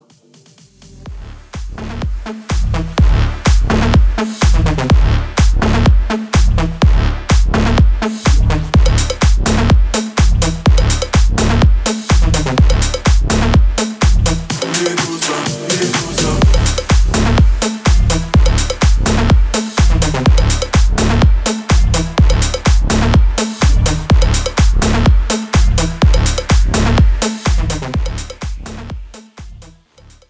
• Качество: 320, Stereo
громкие
remix
Club House
энергичные